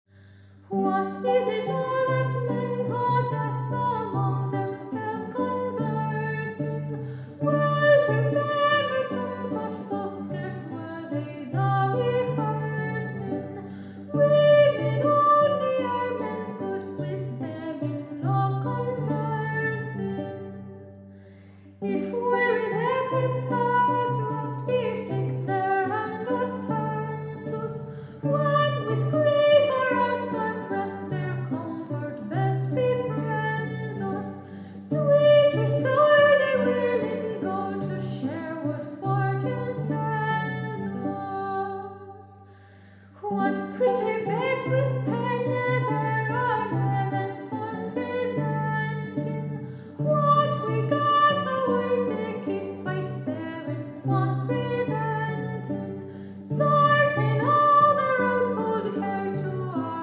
English, Scottish, and Irish lute songs